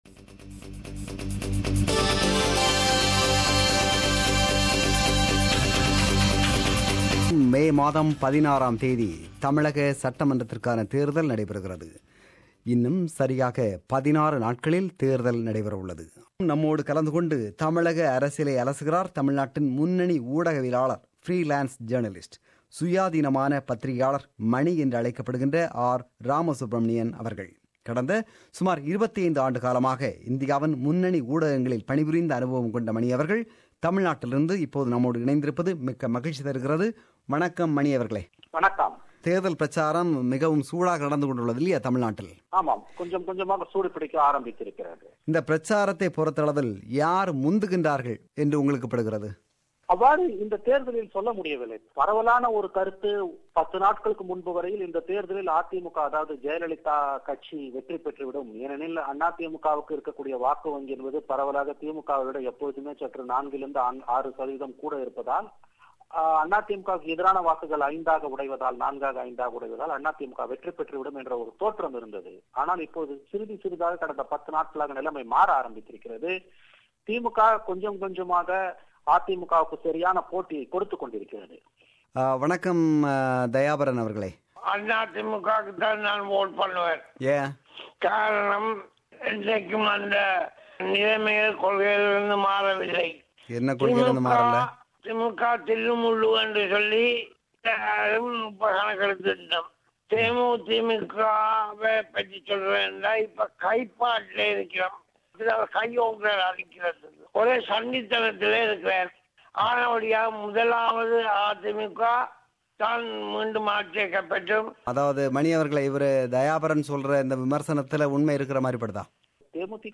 This is the compilation of comments from our listeners who joined Vanga Pesalam program on Friday (29 April).